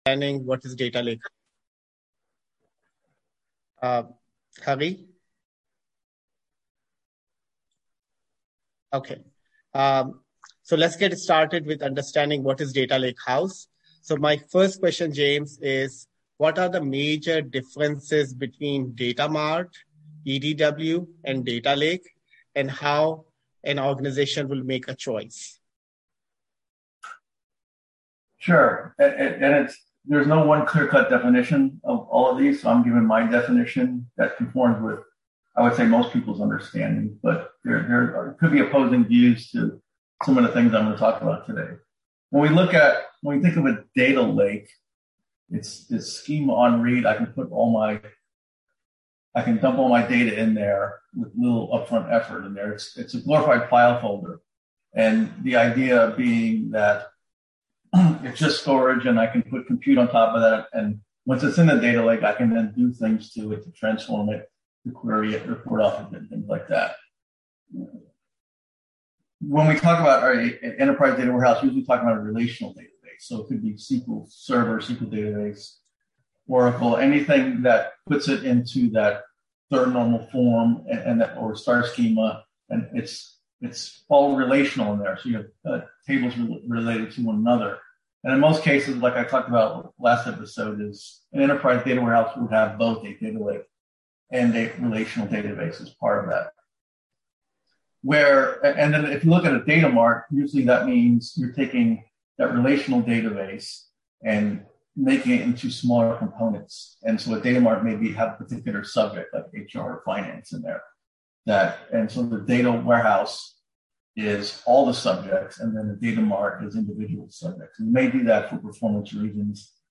A podcast series where data and analytics leaders discuss enterprise AI, data modernization, lakehouse architecture, and scalable analytics strategies.